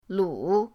lu3.mp3